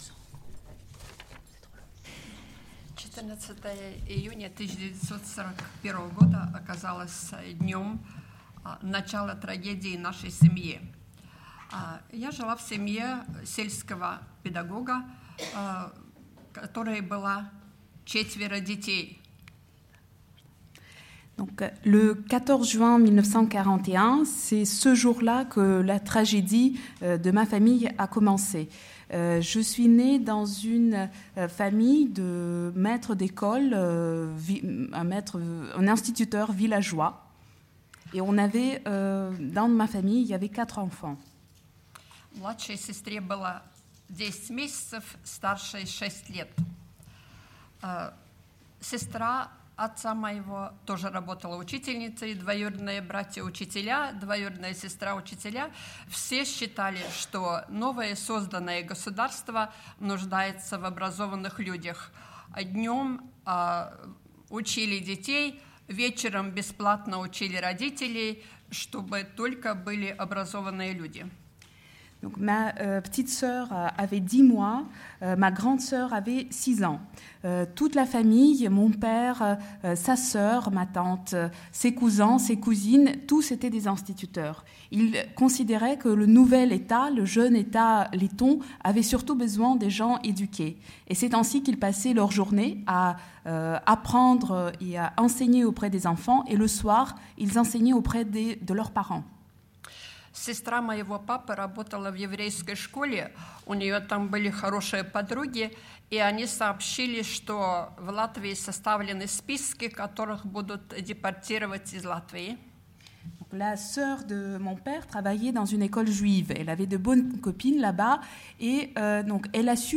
Les archives sonores de l’Europe du Goulag 11 - Témoignage